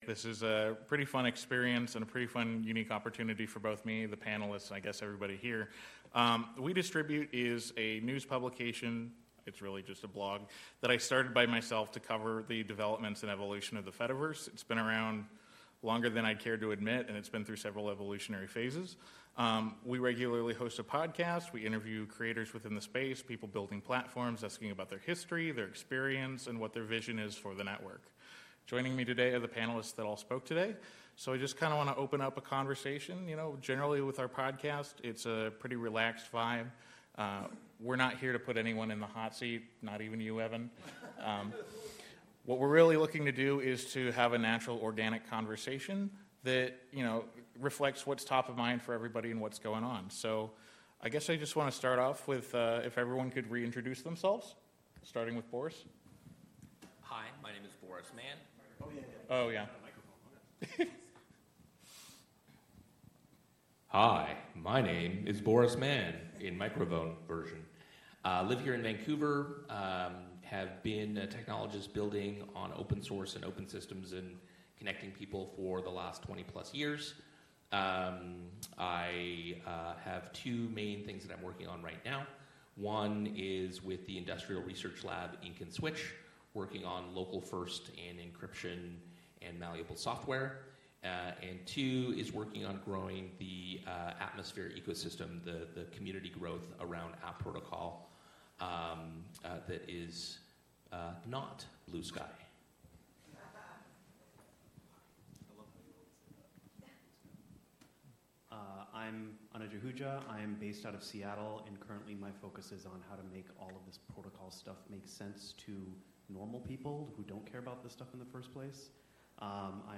This episode is a recording of our live panel from FediCon 2025, which happened in Vancouver, Canada.
This is a recording from our Live Panel at FediCon.
It’s a lively discussion featuring myself and six different guests, who had all presented earlier in the day.